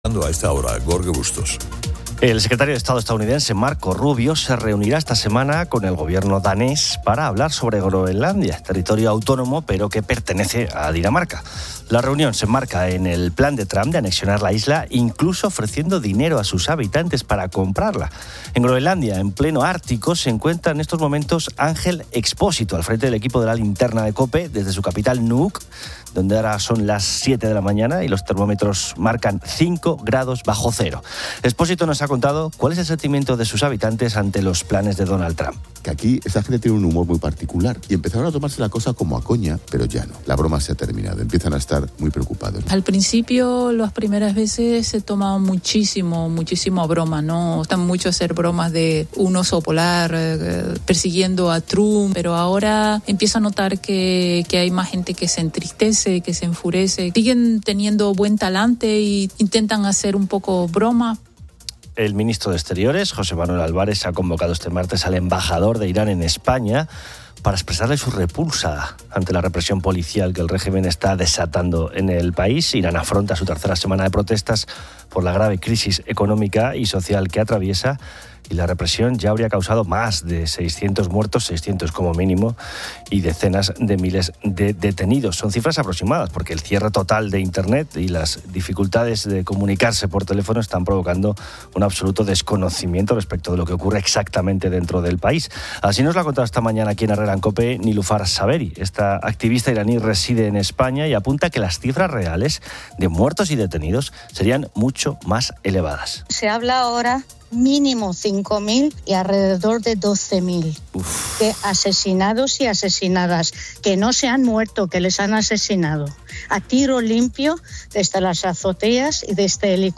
Desde Nuuk, Ángel Expósito reporta la preocupación creciente de sus habitantes.
Oyentes comparten manías como entrar con el pie derecho a la plaza de toros.